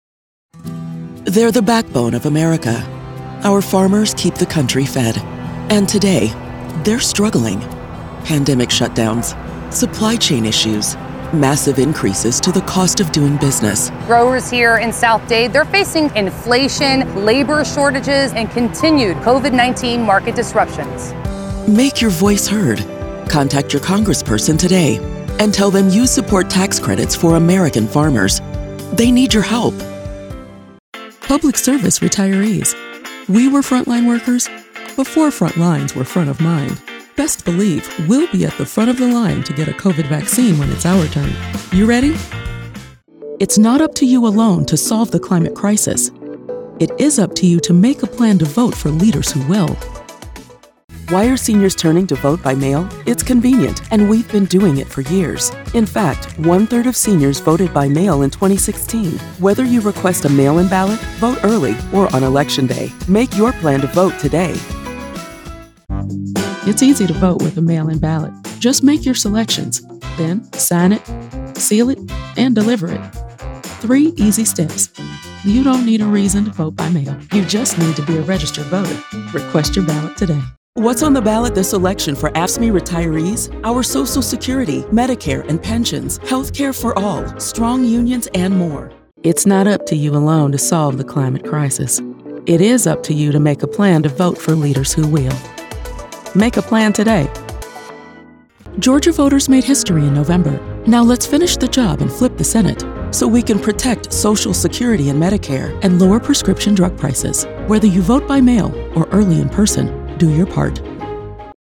Political Voiceover | Let's Win Together!
VOICEOVER DEMO